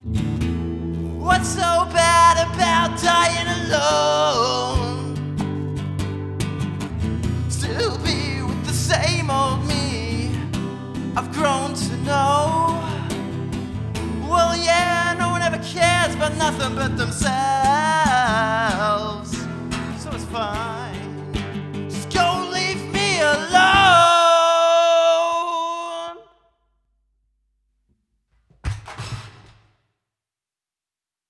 Electronic, Jazz (2023)